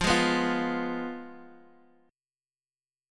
Fdim chord